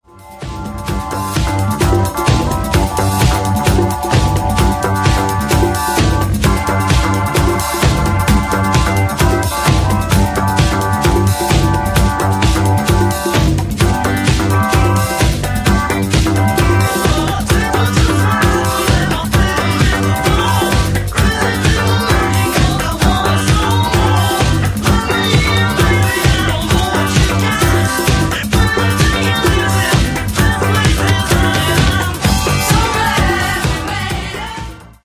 Genere:   Afro | Funk
12''Inch Extended Mix ©1977   15:28